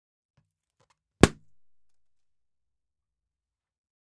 Balloon
Balloon Noise Pop sound effect free sound royalty free Sound Effects